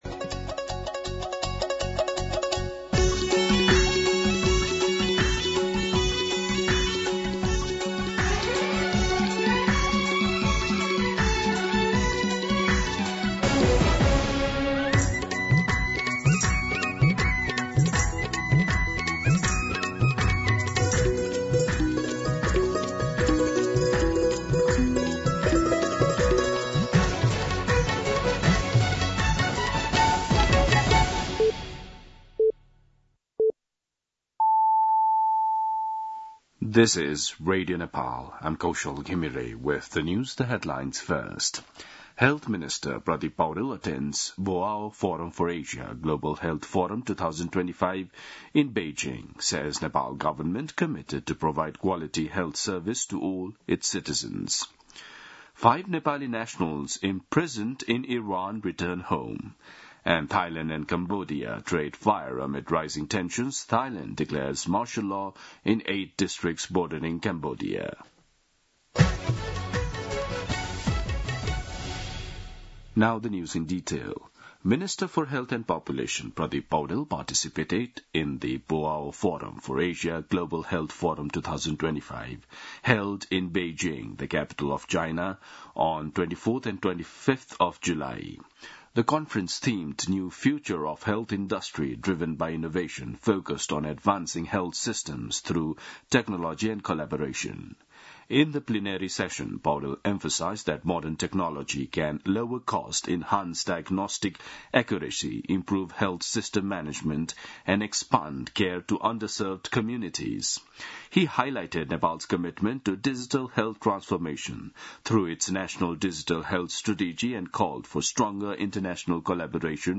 दिउँसो २ बजेको अङ्ग्रेजी समाचार : १० साउन , २०८२
2-pm-English-News-2.mp3